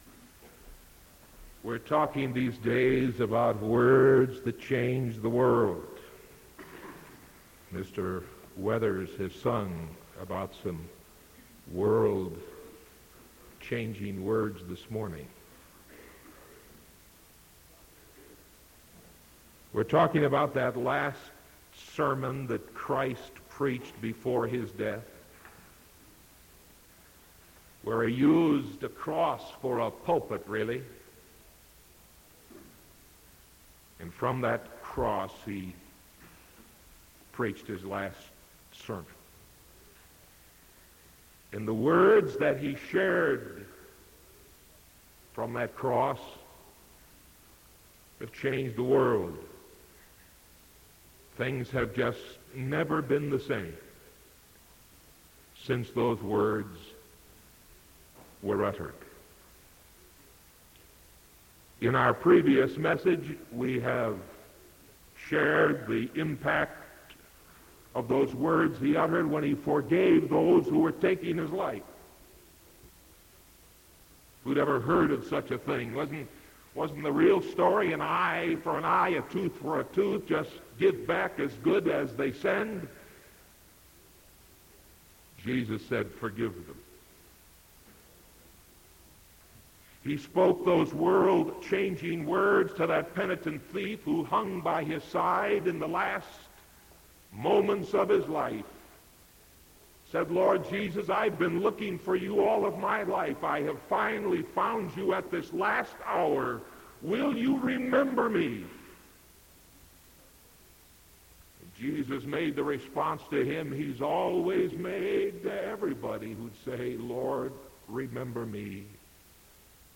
Sermon March 16th 1975 AM